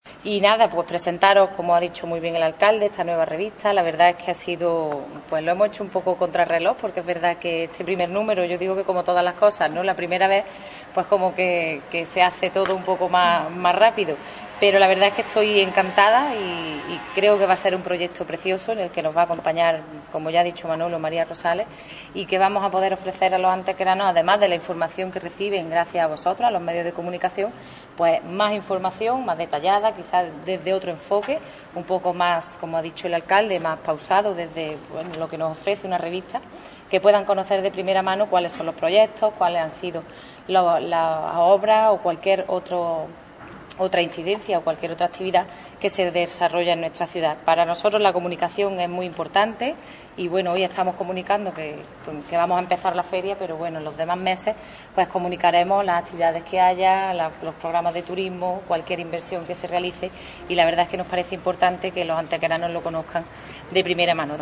Cortes de voz
Audio teniente de alcalde de Cultura, Festejos y Comunicación (Revista Información)   442.24 kb  Formato:  mp3